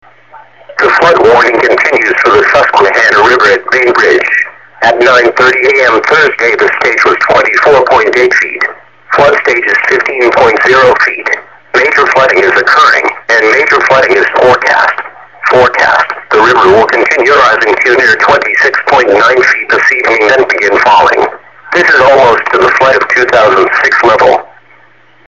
Flood Warning - Susquehanna River at Bainbridge